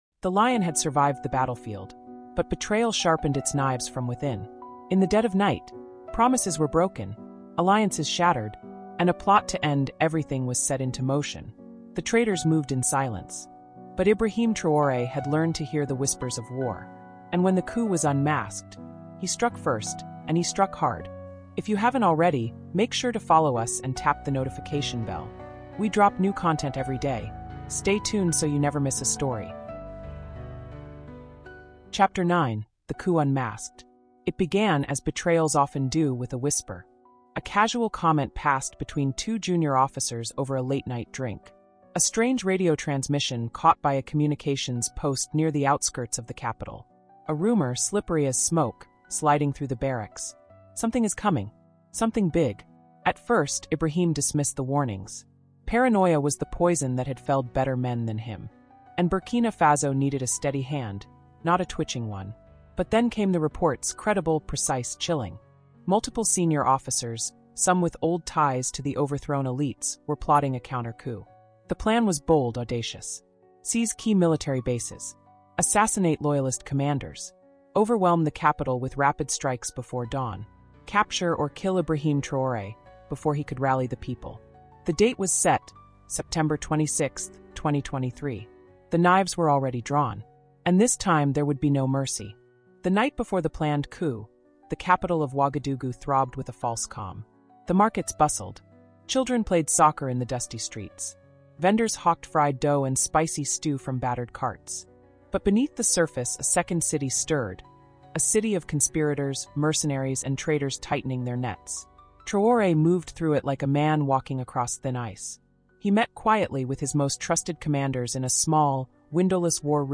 Captain Ibrahim Traoré: africa cultural diplomacy (ch9) | Audiobook